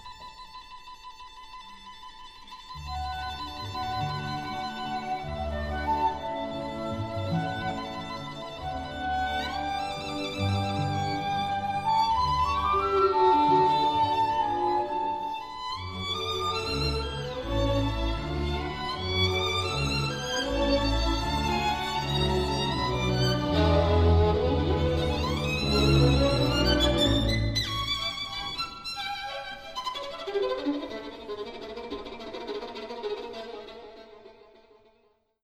For the 1901 festival, Coleridge-Taylor reworked the second movement of his 1896 Symphony in A Minor, adding parts for tuba and harp and changing details of the melody, harmony, and orchestration.